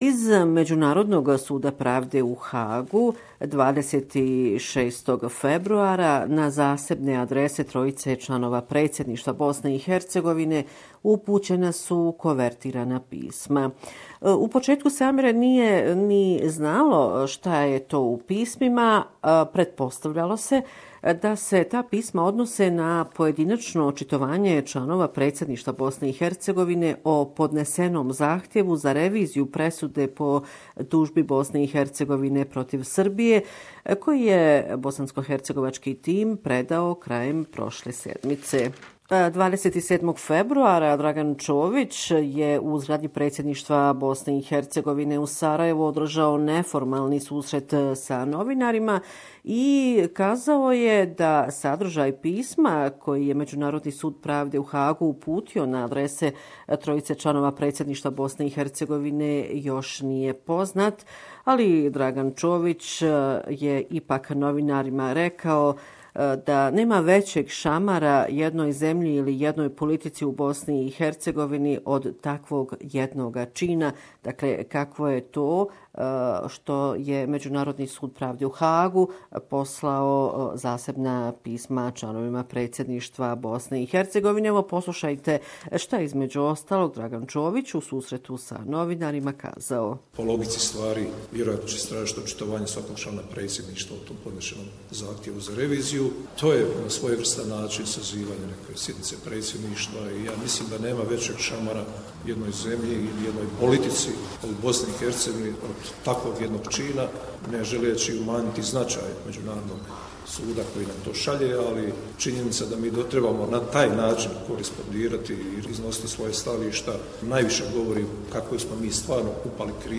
At the end of the visit to Bosnia and Herzegovina, High Representative for Foreign Affairs and Security Policy/Vice-President of the European Commission Federica Mogherini met with the leaders of political parties to discuss the way forward on the country's path towards European Union. Report from Bosnia and Herzegovina